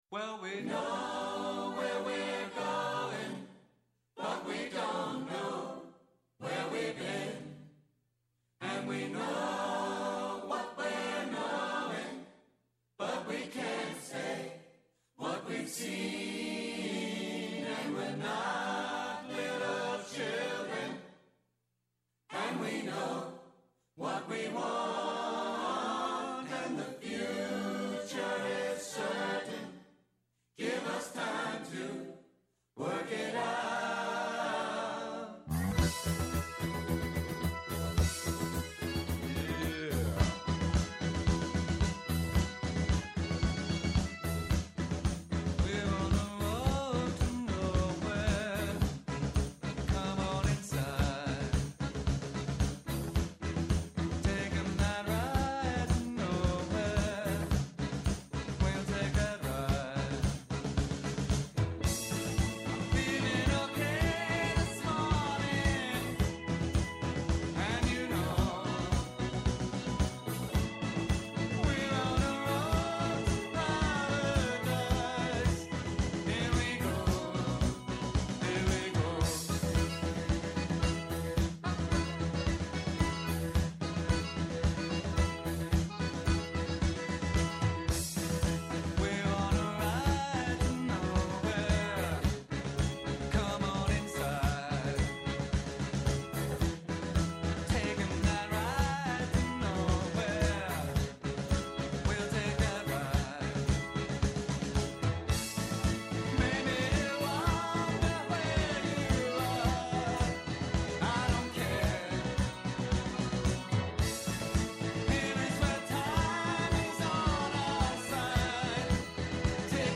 Σήμερα καλεσμένος ο Κωστής Χατζηδάκης , Υπουργός Εθνικής Οικονομίας και Οικονομικών .